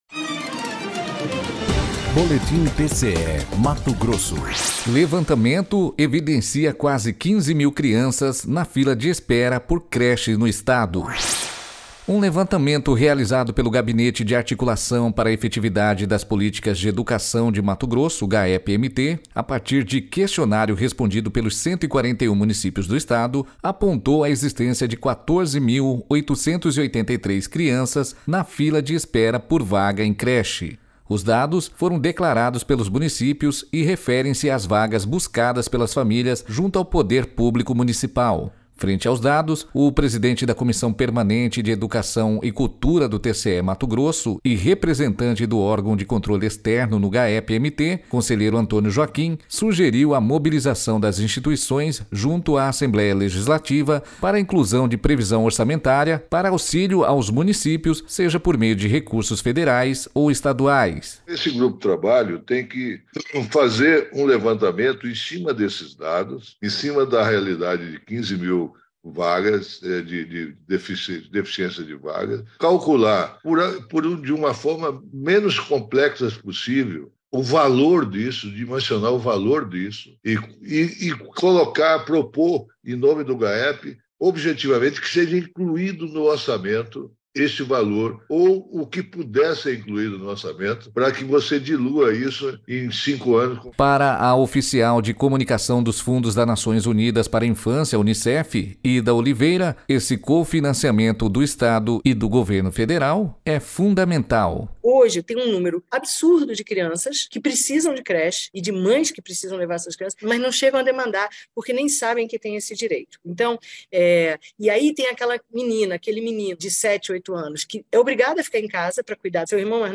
Sonora: Antonio Joaquim – conselheiro presidente da Comissão Permanente de Educação e Cultura do TCE-MT e representante do órgão de controle externo no Gaepe-MT